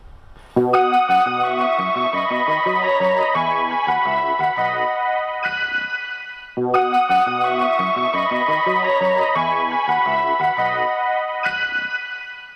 Using from effect sound collection.
Departure merody